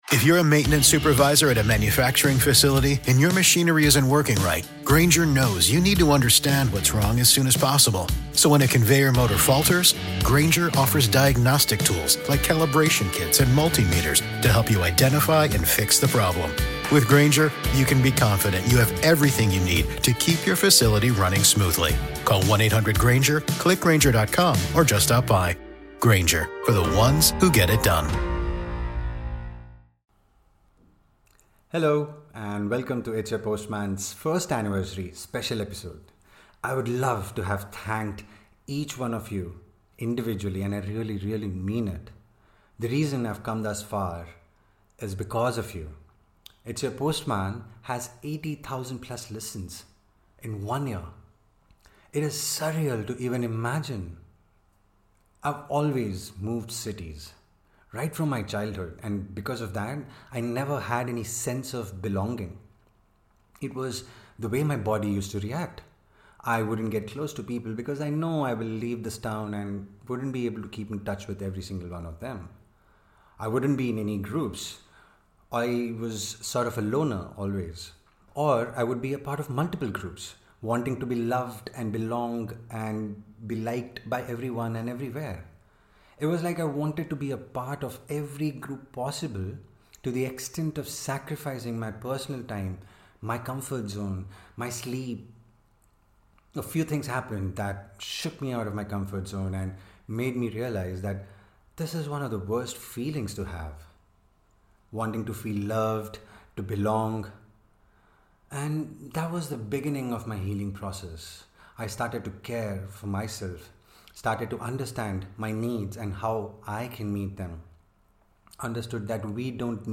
A special note from a very close friend, a beautiful song in a beautiful voice.